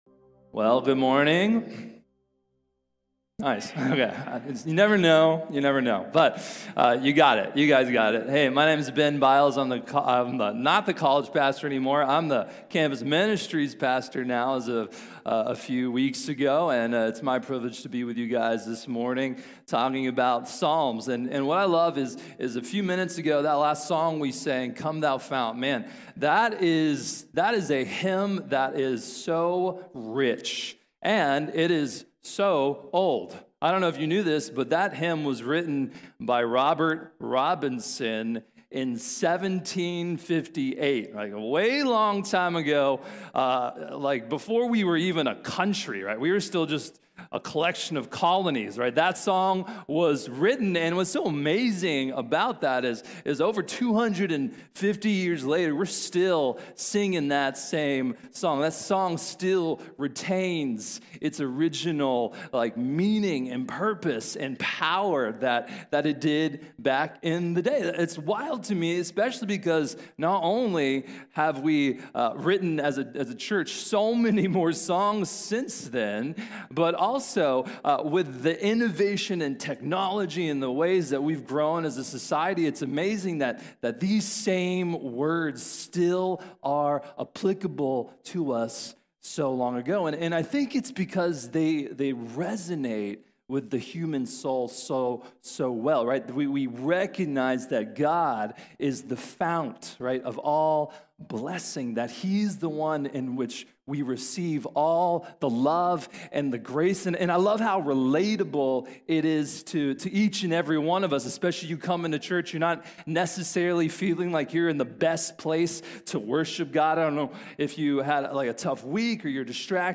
The Overwhelming Love of God | Sermon | Grace Bible Church